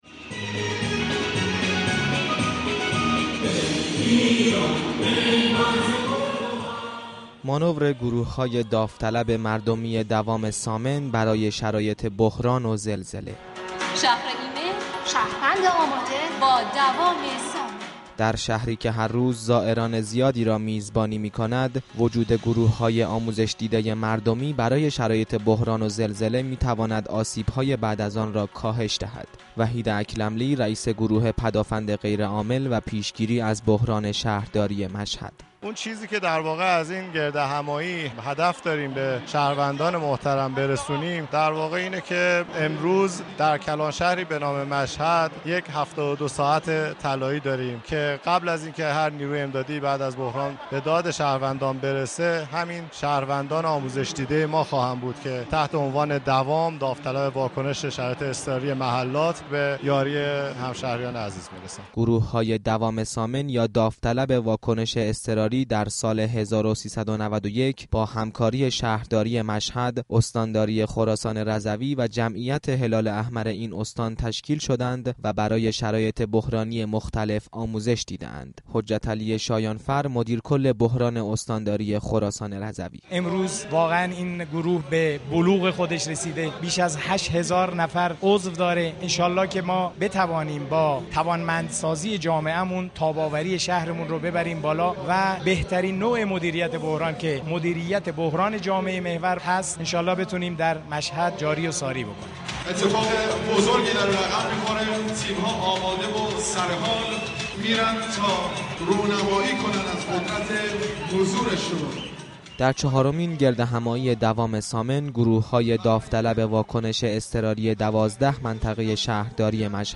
چهارمین گردهمایی بزرگ دوام ثامن همزمان با روز ملی مقابله با زلزله و بلایای طبیعی، با حضور جمعی از مدیران شهری برگزار شد